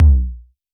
Index of /musicradar/retro-drum-machine-samples/Drums Hits/WEM Copicat
RDM_Copicat_SY1-Tom03.wav